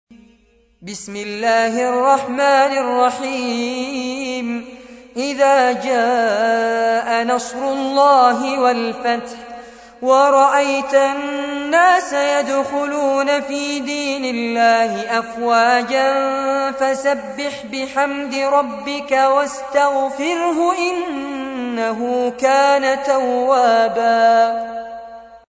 تحميل : 110. سورة النصر / القارئ فارس عباد / القرآن الكريم / موقع يا حسين